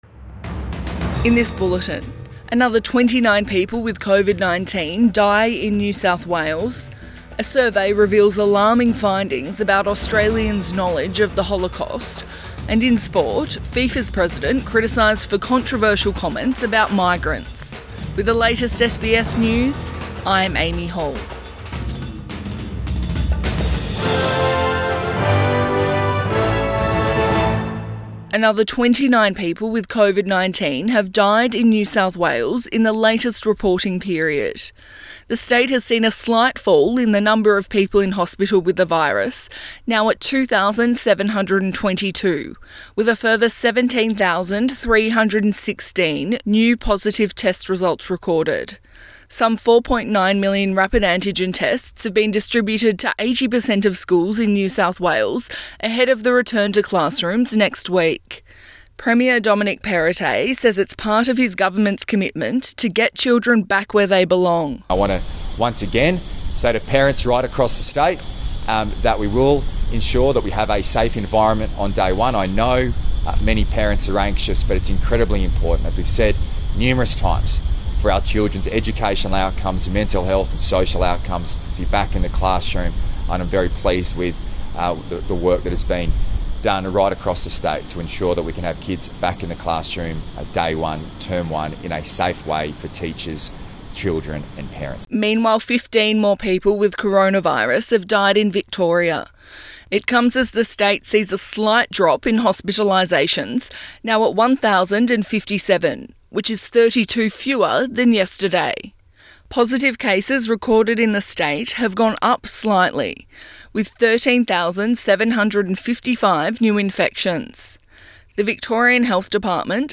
Midday bulletin 27 January 2022